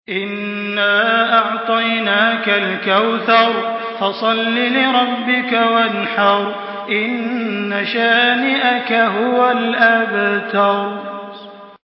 Surah আল-কাউসার MP3 by Makkah Taraweeh 1424 in Hafs An Asim narration.
Murattal